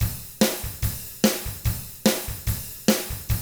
146ROCK T4-R.wav